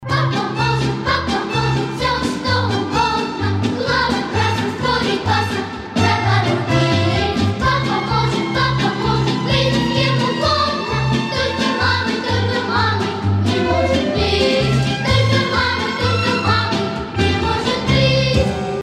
• Качество: 256, Stereo
веселые
хор
дети